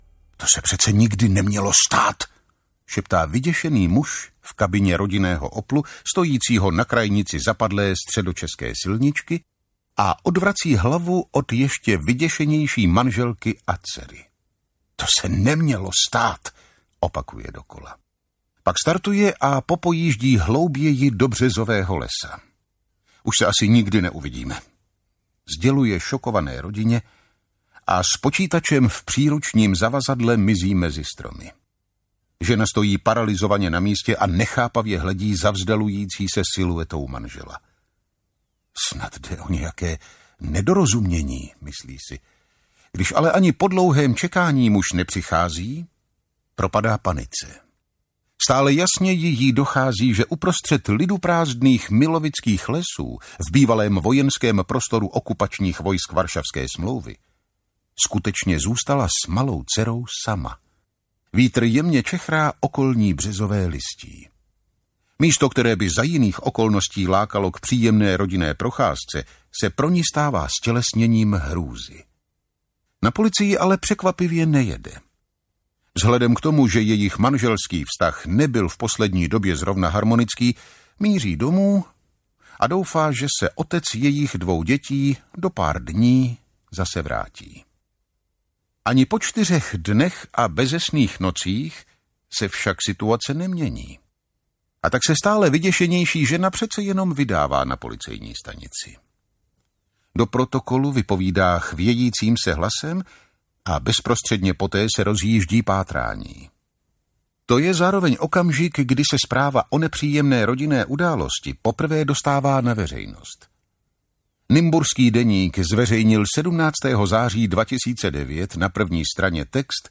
Putinovi agenti audiokniha
Ukázka z knihy
putinovi-agenti-audiokniha